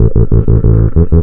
FINGERBSS8-L.wav